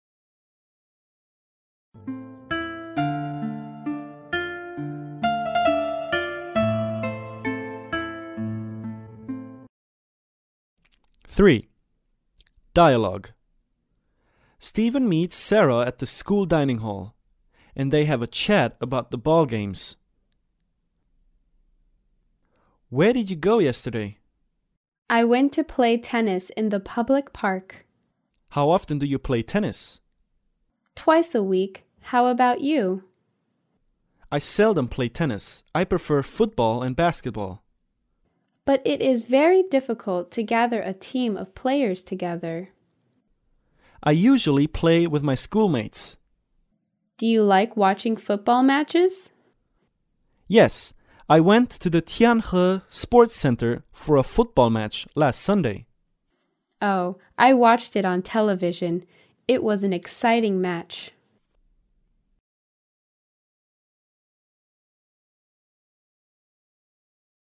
对话